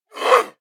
attack_13.ogg